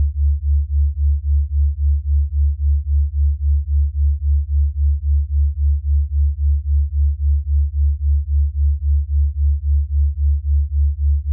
The second file puts both tones in both ears so that actuall beats are reaching the eardrums.
Both tones in each ear, beats formed at eardrums